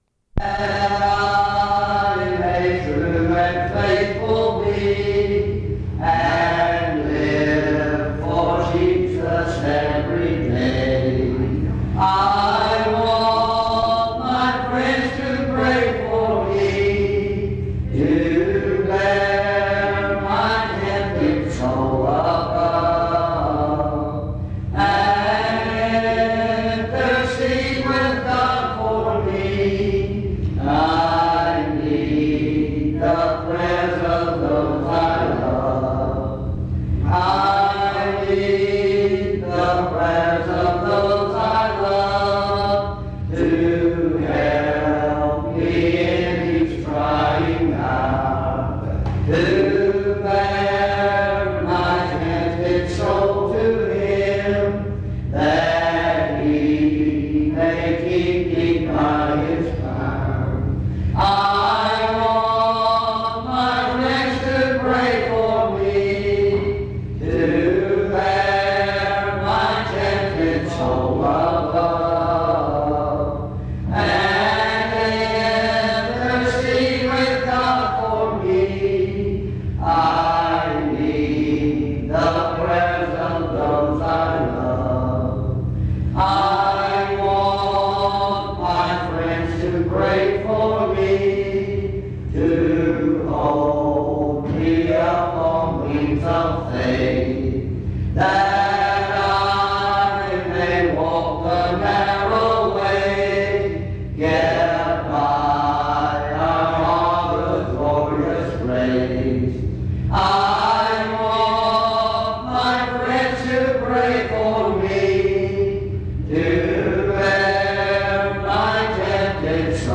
Reidsville Lindsey St. Primitive Baptist Church